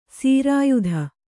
♪ sīrāyudha